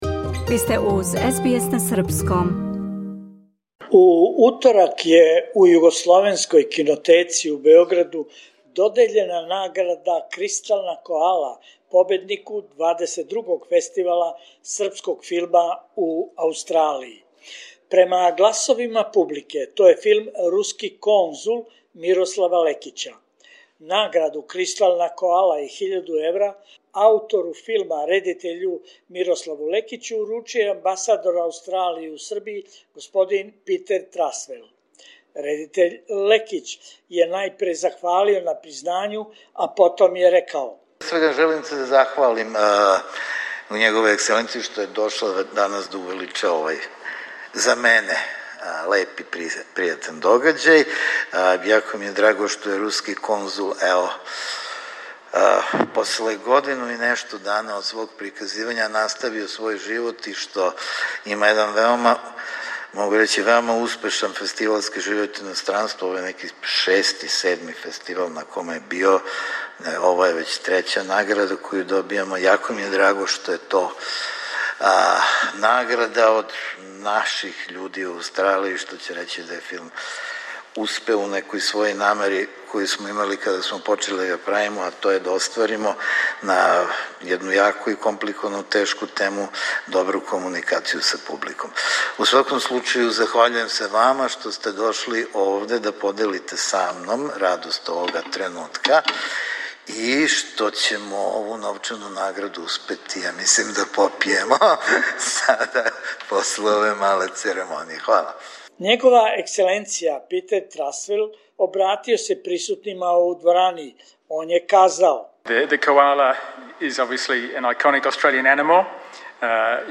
У сали Душан Макавејев Југословенске кинотеке у Београду у уторак је уприличена свечана церемонија доделе награде Српског филмског фестивала у Аустралији, редитељу овогодишњег победничког филма "Руски конзул" Мирославу Лекићу.